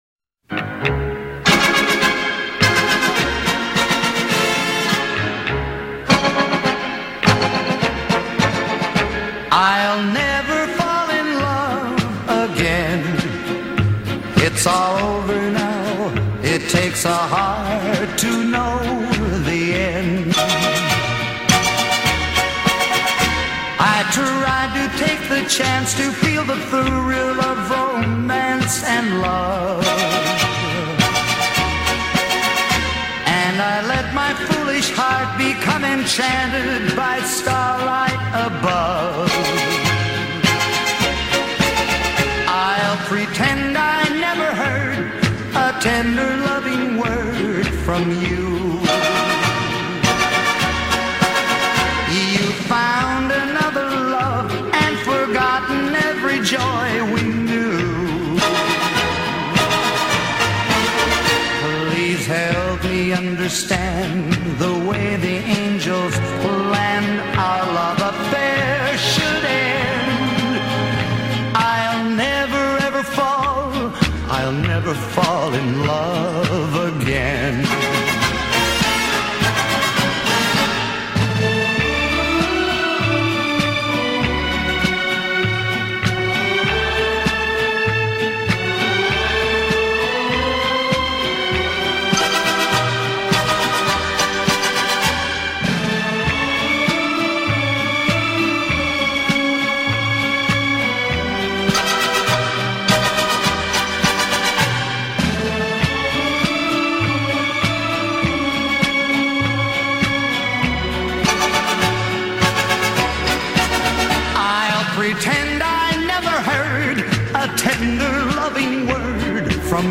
американского певца